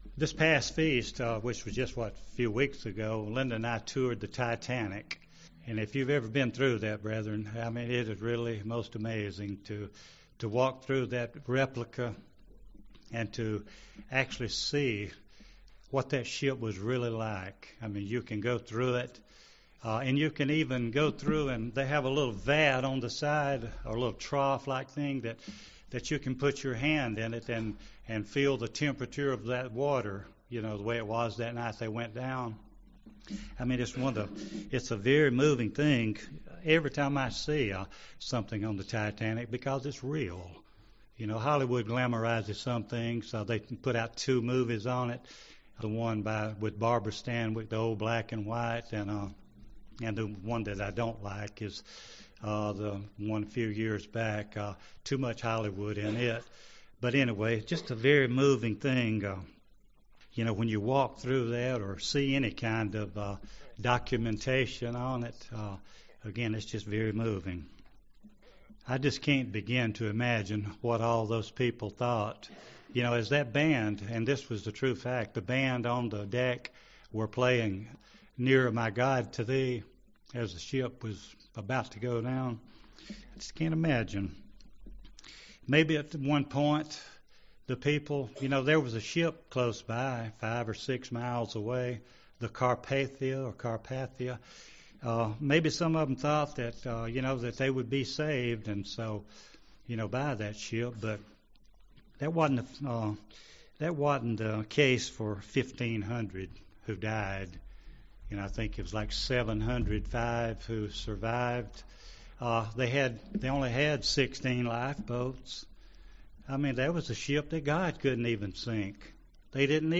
This sermon shows from scripture that there is a time element involved in a person's calling to salvation.
Given in Gadsden, AL